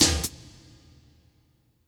snare04.wav